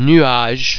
The French [u+vowel ] sound is actually the French [ u ] sound pronounced before an [ i ], [ y ], [e] or [a] but perhaps shorter and more like just a transition sound.
ui_nuage.mp3